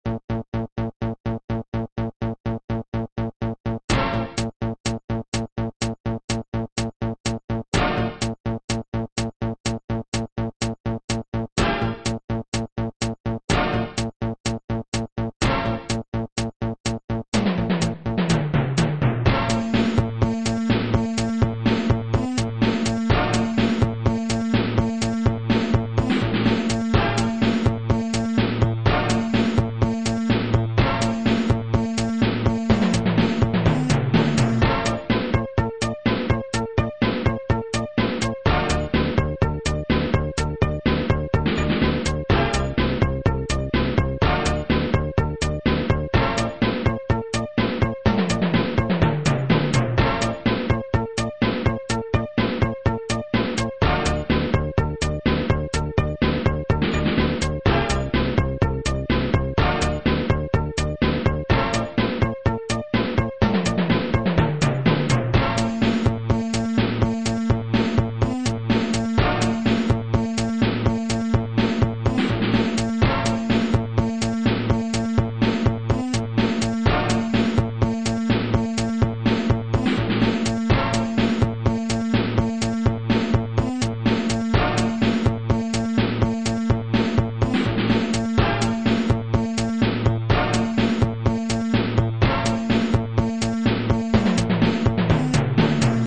Digital title music
This game features digital title music